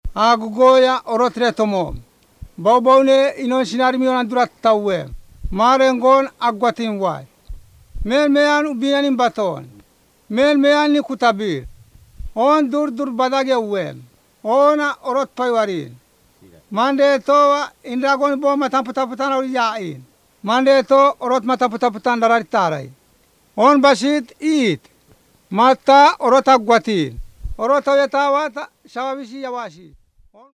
Words of Life recordings contain short Bible stories, evangelistic messages and songs. They explain the way of salvation and give basic Christian teaching. Most use a storytelling approach. These are recorded by mother-tongue speakers